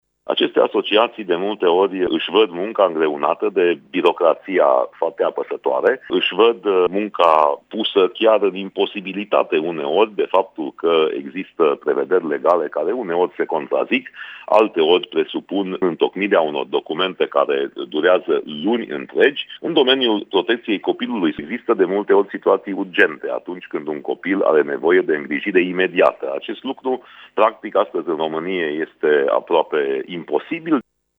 În domeniul protecției copilului apar mereu situații care trebuie soluționate urgent și acest lucru nu prea este posibil, spune inițiatorul mesei rotunde, europarlamentarul Iuliu Winkler: